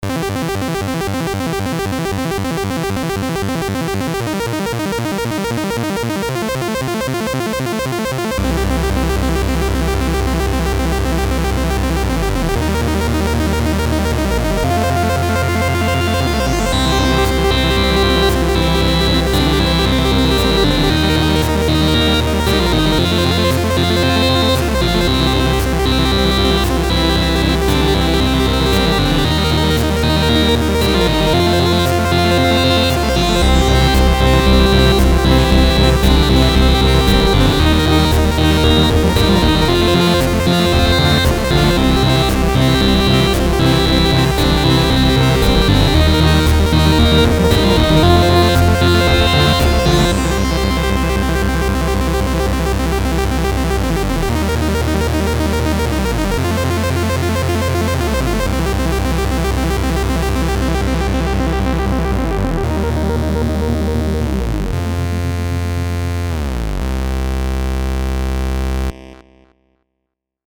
Made in FL Studio using Kepler Exo.
solo instrument